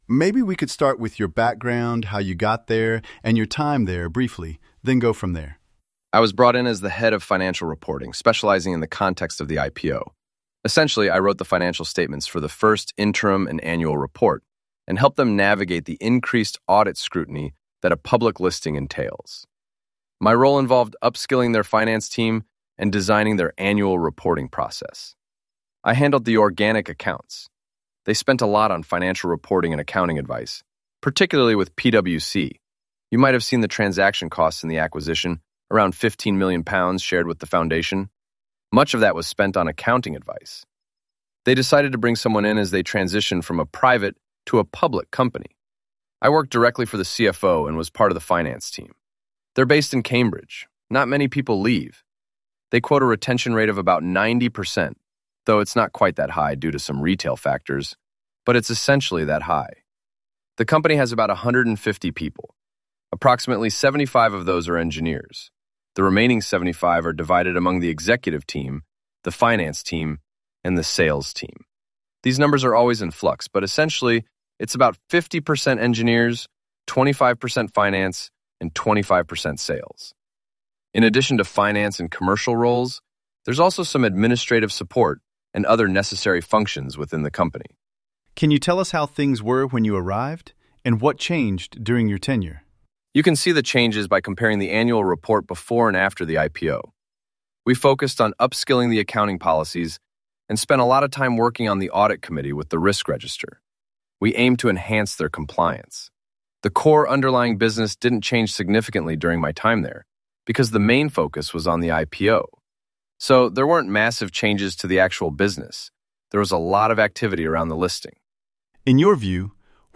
In Practise Interviews